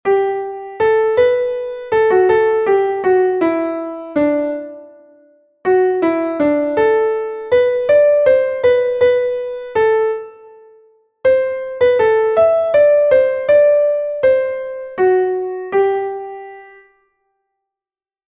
Entoación a capella
Melodía en 6/8 en Fa M